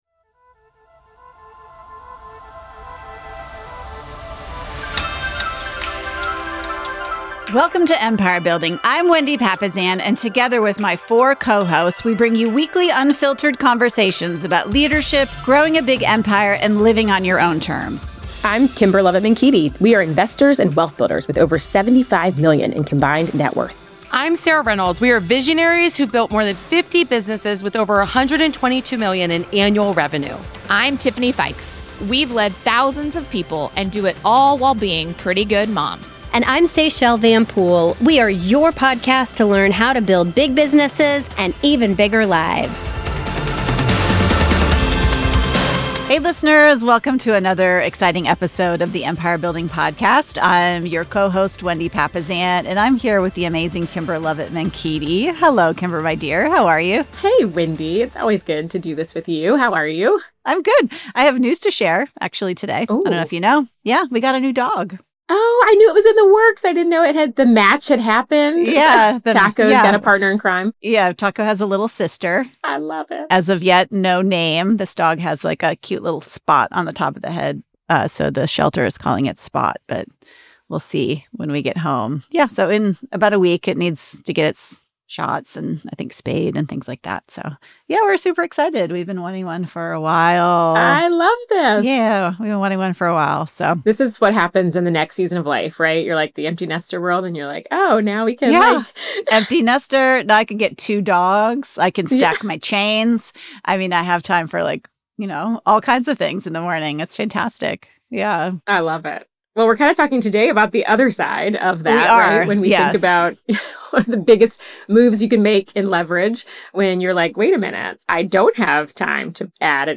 dr jordan b peterson motivational speech